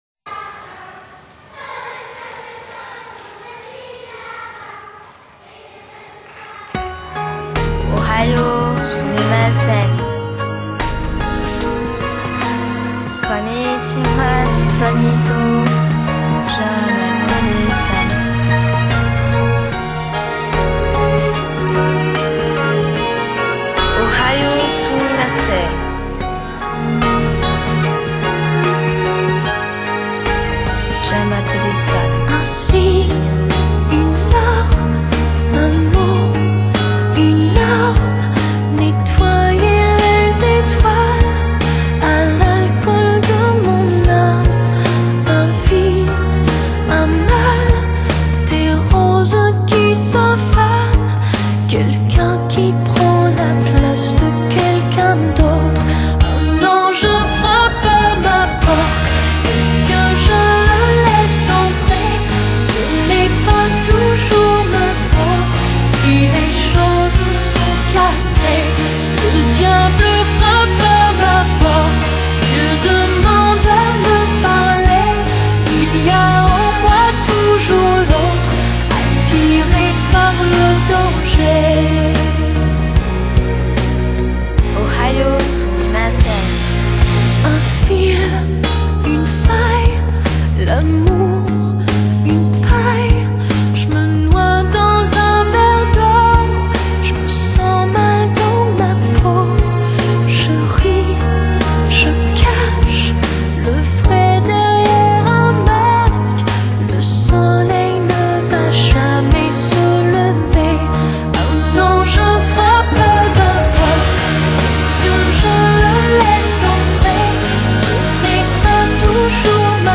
Genre: Pop, French, Chanson